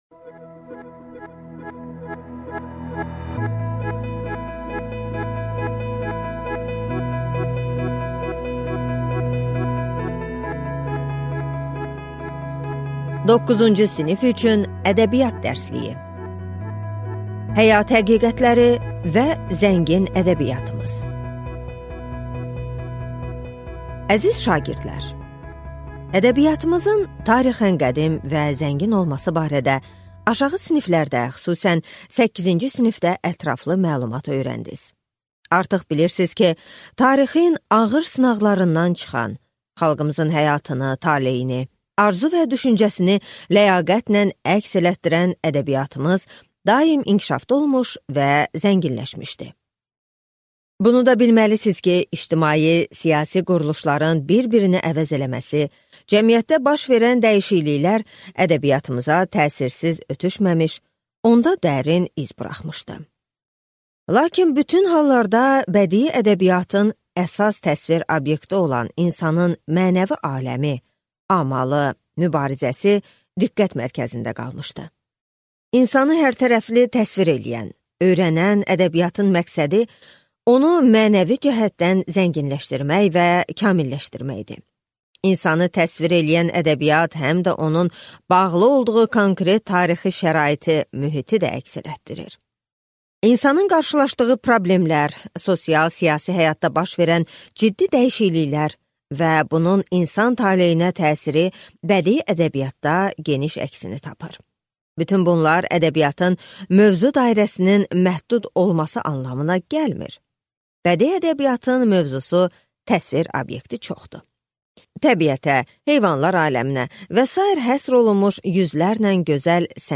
Аудиокнига Ədəbiyyat dərsliyi. 9-cu sinif | Библиотека аудиокниг